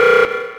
ALARM_Distorted_Echo_loop_stereo.wav